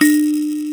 SANZA 4 G2.WAV